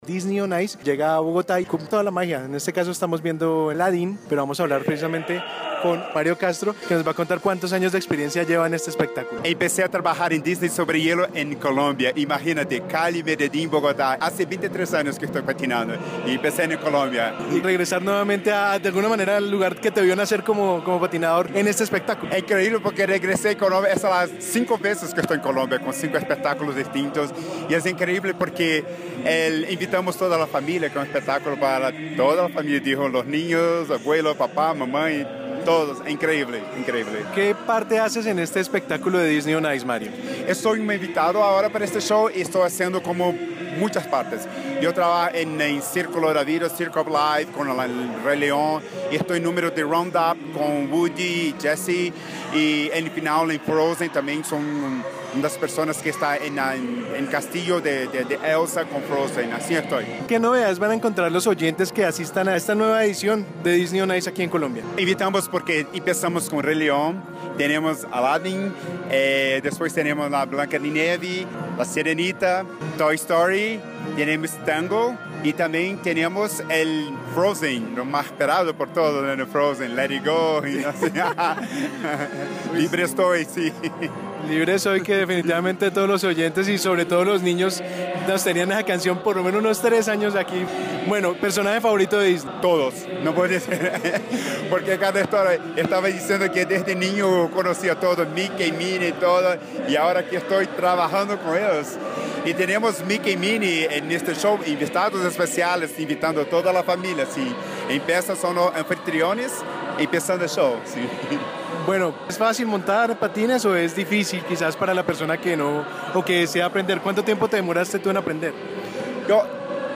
Entrevista-Disney-On-ice.mp3